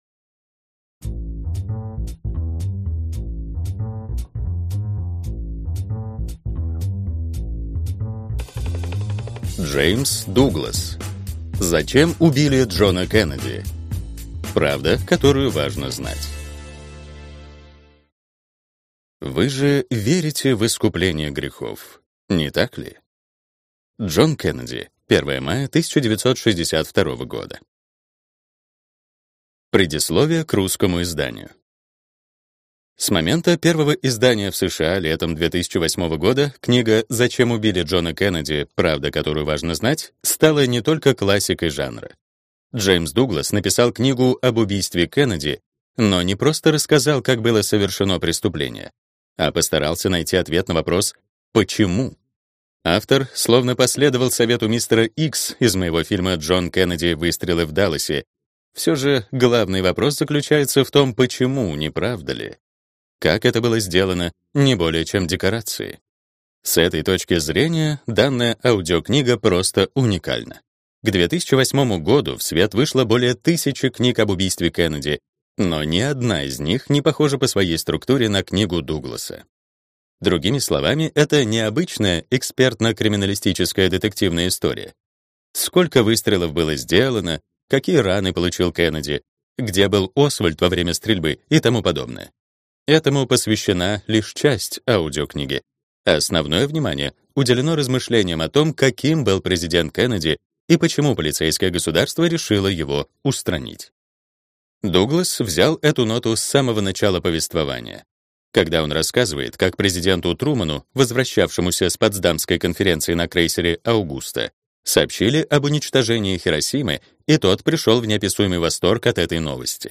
Аудиокнига Зачем убили Джона Кеннеди. Правда, которую важно знать | Библиотека аудиокниг